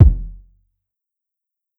Space Kick.wav